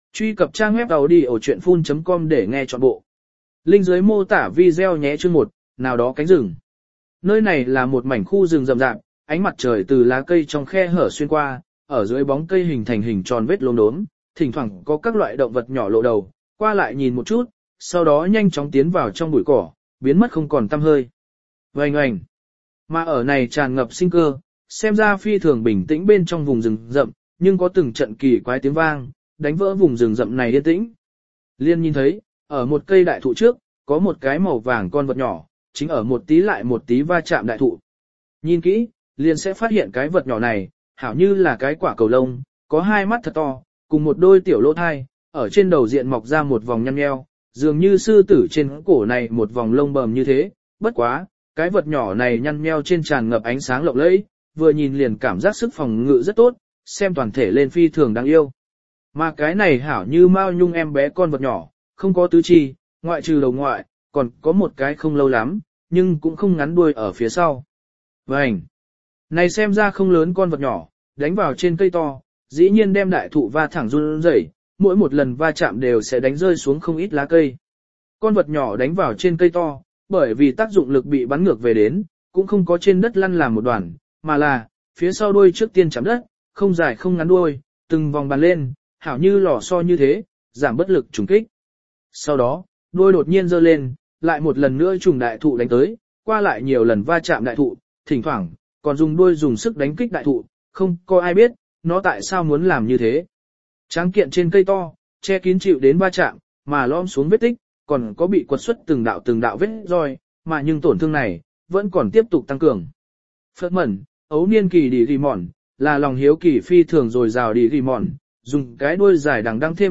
Sư Tử Thú Lữ Đồ Audio - Nghe đọc Truyện Audio Online Hay Trên TH AUDIO TRUYỆN FULL